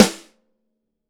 ST DRYSN2.wav